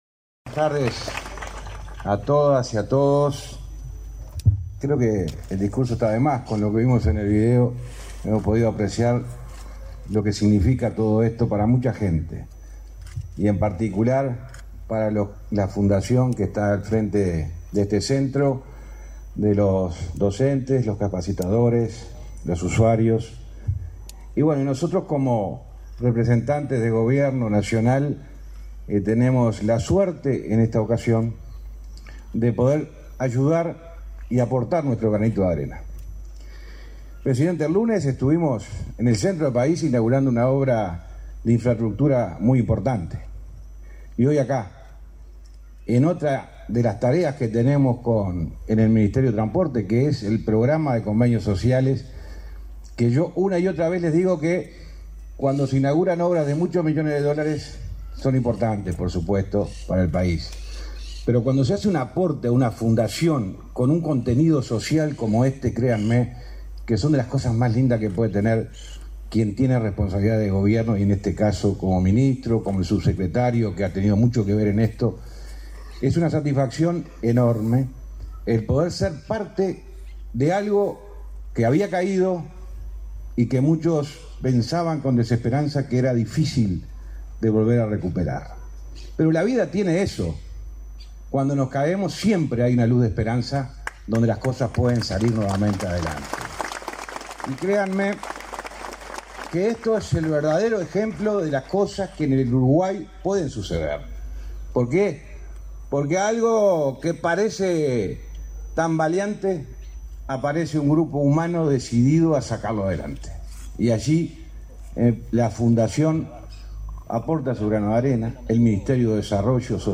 Conferencia de prensa por inauguración de obras del centro Tiburcio Cachón
Conferencia de prensa por inauguración de obras del centro Tiburcio Cachón 15/02/2023 Compartir Facebook X Copiar enlace WhatsApp LinkedIn Con la presencia del presidente de la República, Luis Lacalle Pou, el Ministerio de Desarrollo Social (Mides) y el Ministerio de Transporte y Obras Públicas (MTOP) inauguraron, este 15 de febrero, las obras de remodelación y acondicionamiento del centro de rehabilitación para personas con discapacidad visual Tiburcio Cachón. Participaron del evento el ministro del Mides, Martín Lema; y el ministro del MTOP, José Luis Falero.